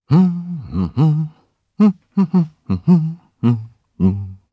Kibera-Vox_Hum_jp.wav